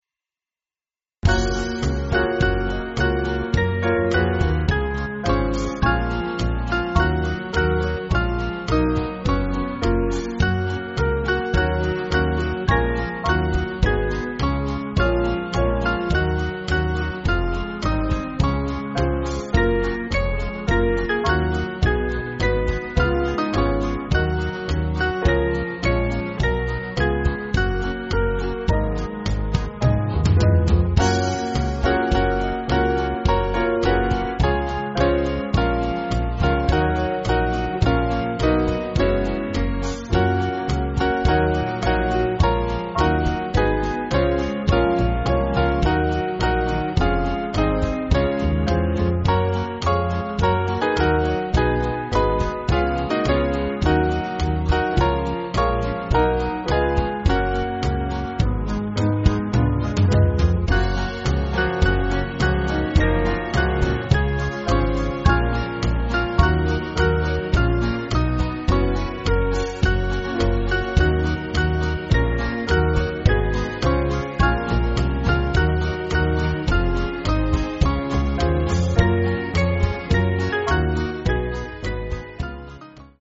Small Band
(CM)   5/Gb-G